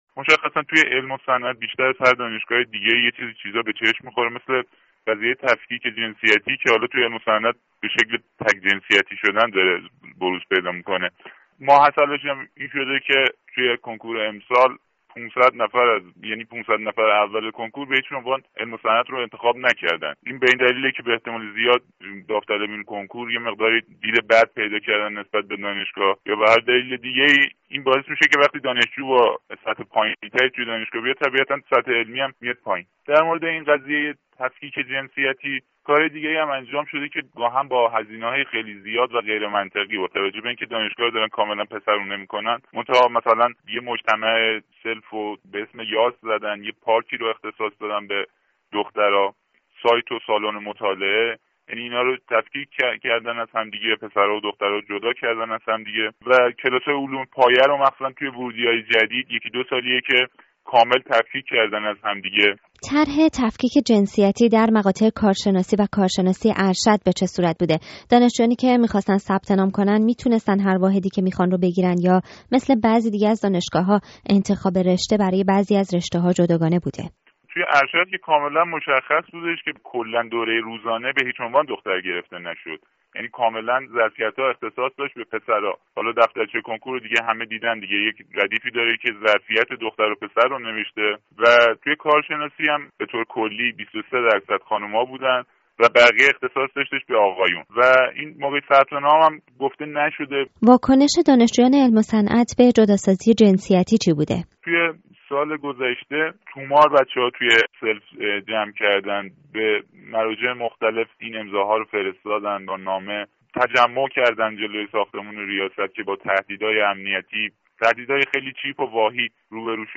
راديو فردا درباره اجرای طرح تفکيک جنسيتی در دانشگاه علم و صنعت و فشارهای وارده بر دانشجويان همزمان با آغاز سال تحصيلی با يکی از فعالان دانشجويی در اين دانشگاه گفت و گو کرده است.